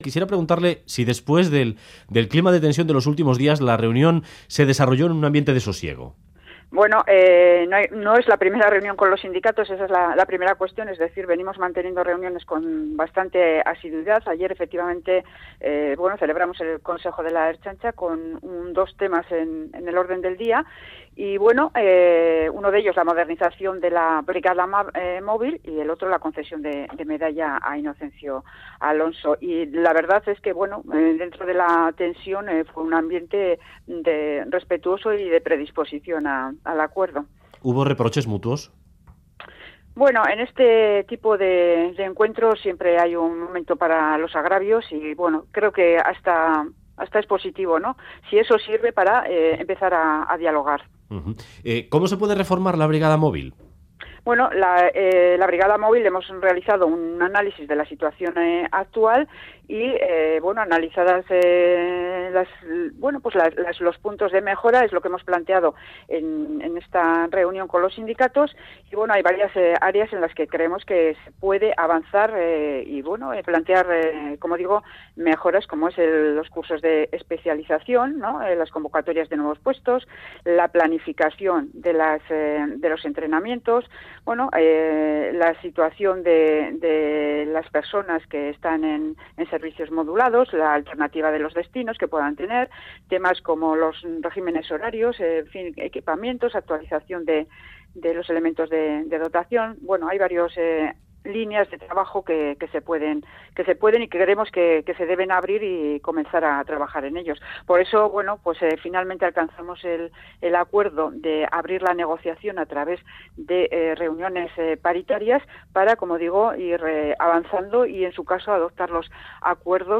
Audio: La consejera de seguridad, Estefanía Beltrán de Heredia, confirma en Radio Euskadi que el expediente a los ertzainas que se concentraron ante el Parlamento sigue adelante, según la "ley mordaza"